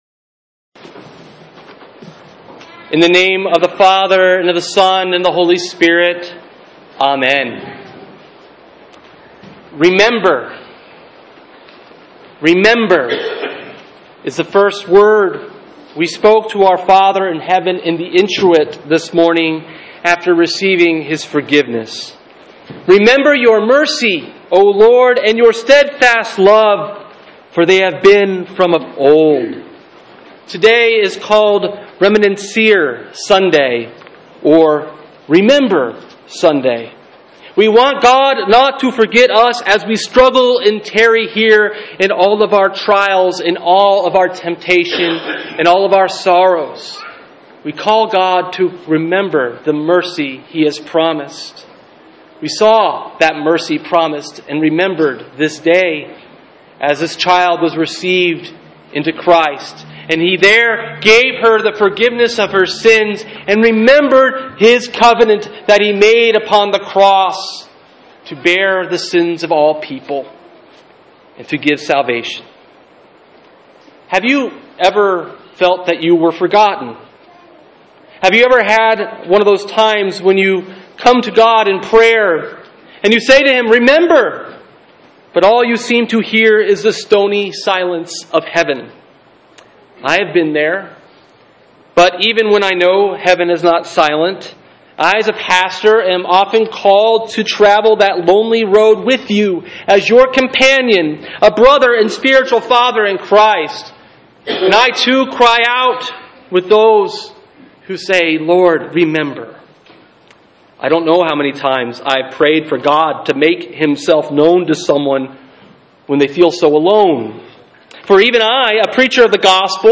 Sermon: Lent 2 Matthew 15:21-28